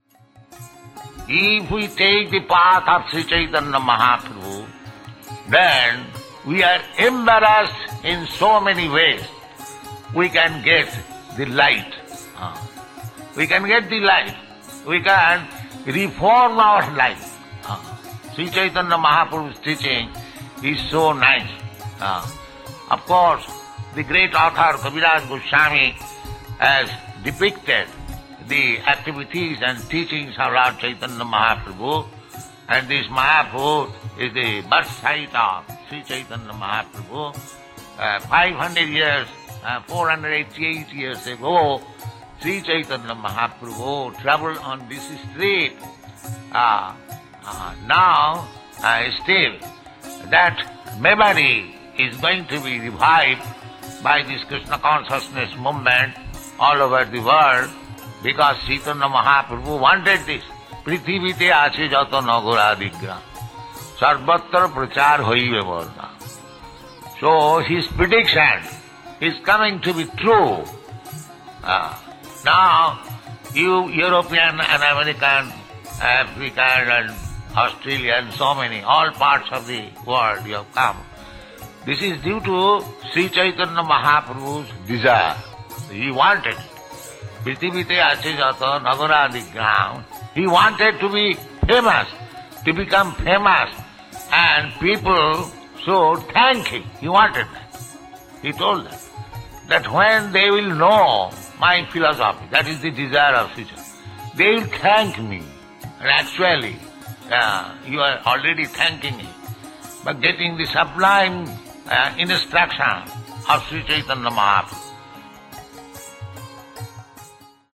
(740301 - Lecture CC Adi 07.01 - Mayapur)